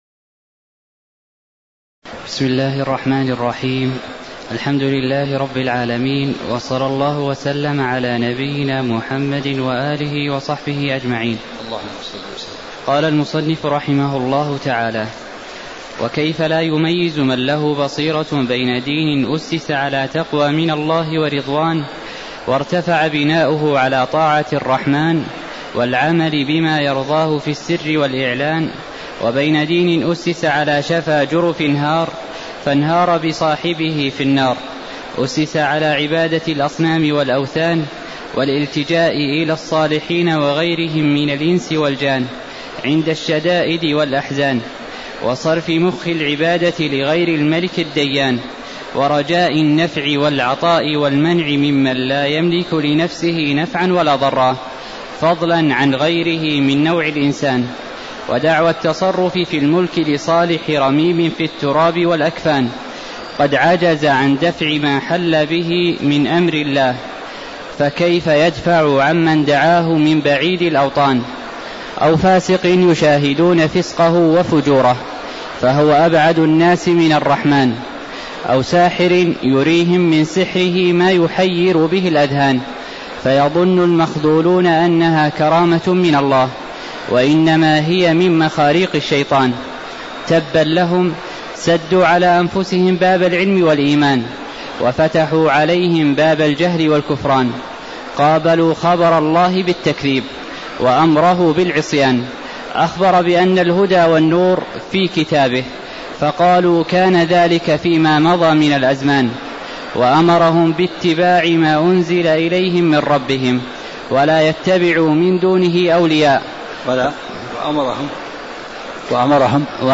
تاريخ النشر ٢٨ شعبان ١٤٣٦ هـ المكان: المسجد النبوي الشيخ: عبدالعزيز الراجحي عبدالعزيز الراجحي وقوله: وكيف لا يميّز من له بصيره (03)